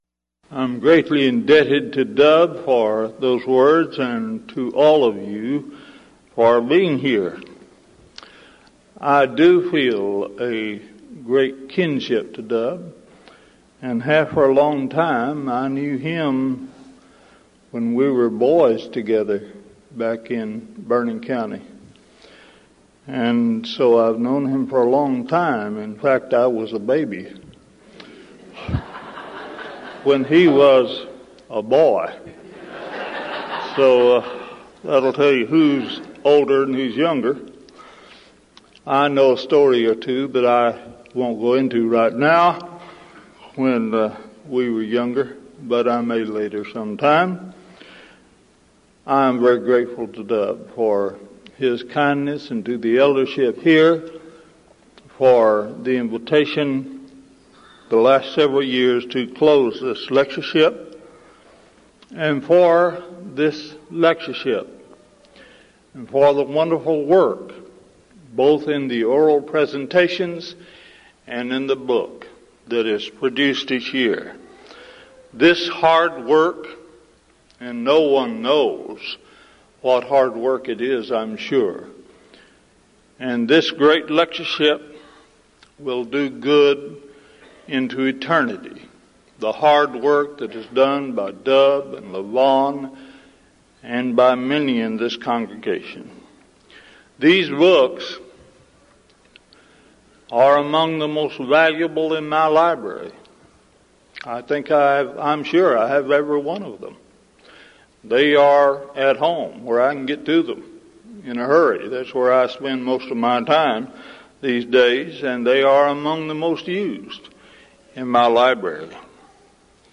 Event: 1998 Denton Lectures Theme/Title: Studies in the Books of I, II Peter and Jude